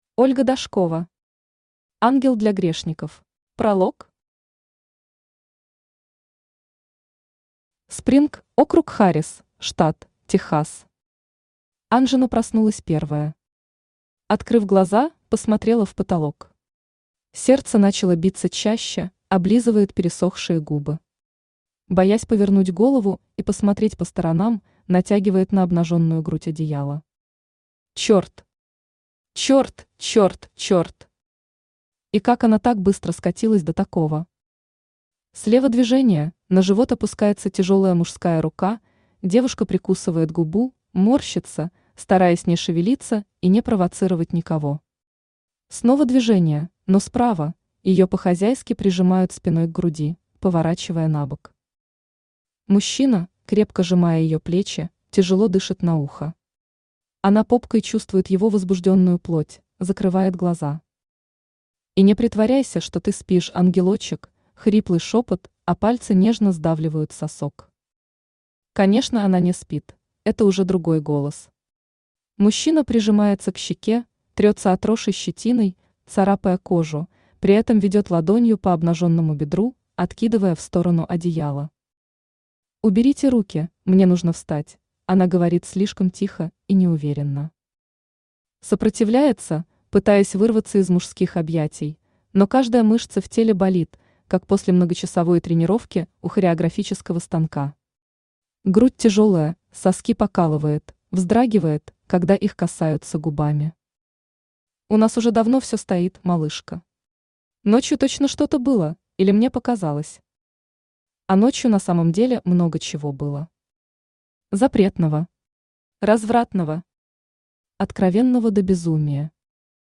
Аудиокнига Ангел для грешников | Библиотека аудиокниг
Aудиокнига Ангел для грешников Автор Ольга Викторовна Дашкова Читает аудиокнигу Авточтец ЛитРес.